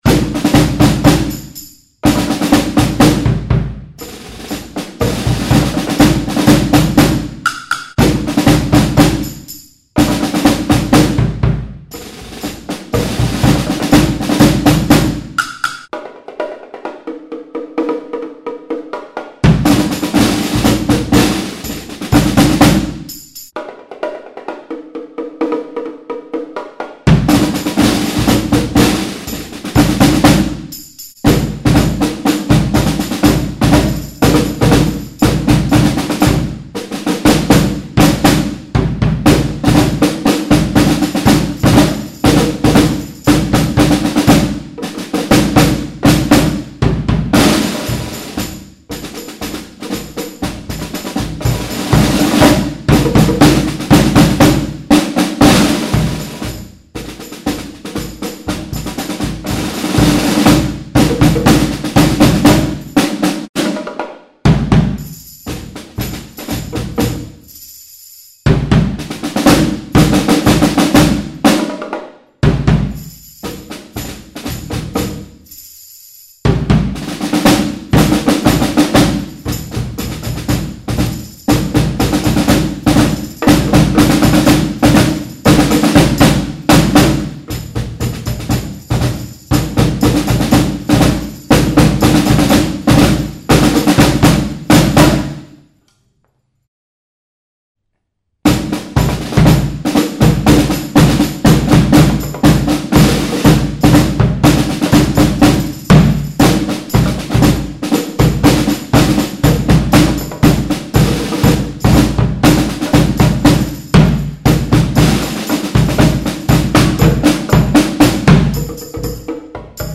two equivalent practice pieces for young drum band